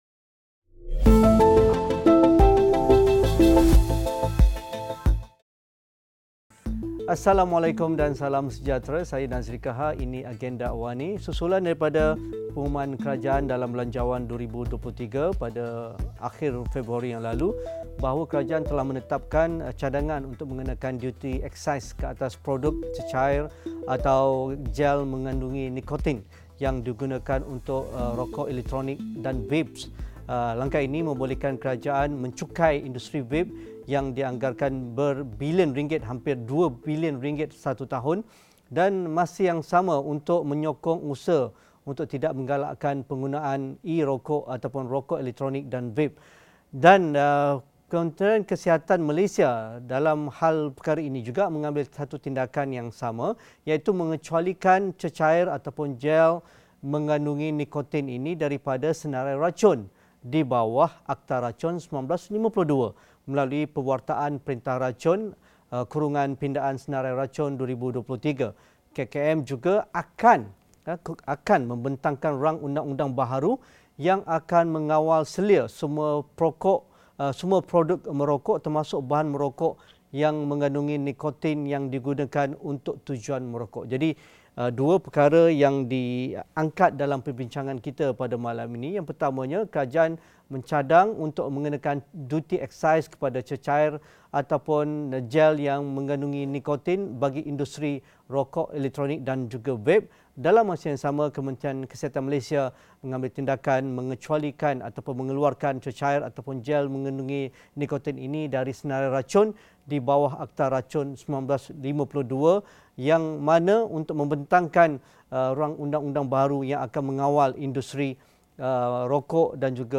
Keputusan kerajaan untuk mengeluarkan cecair nikotin daripada Akta Racun bagi membolehkan duti eksais dikenakan ke atas vape mengundang pelbagai reaksi. Sejauh mana impaknya keputusan tersebut? Diskusi 8.30 malam